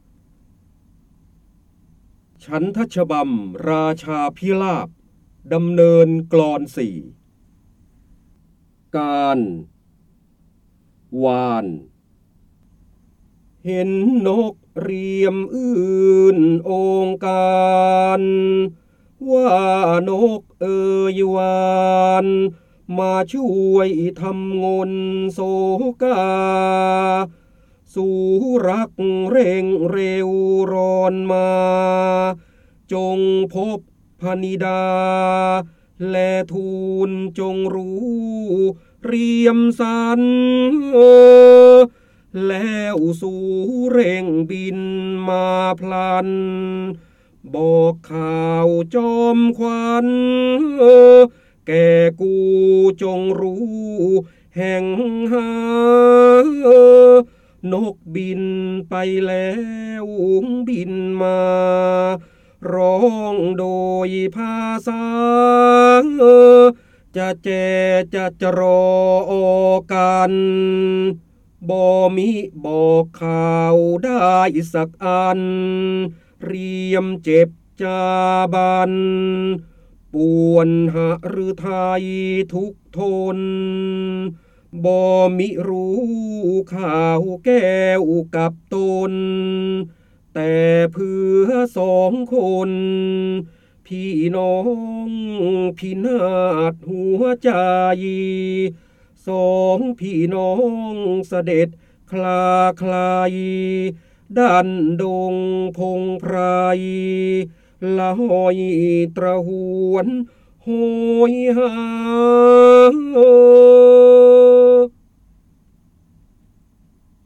เสียงบรรยายจากหนังสือ จินดามณี (พระโหราธิบดี) ฉันทฉบำราชาพิลาปดำเนอรกลอน ๔
คำสำคัญ : พนะเจ้าบรมโกศ, พระโหราธิบดี, จินดามณี, ร้อยแก้ว, ร้อยกรอง, การอ่านออกเสียง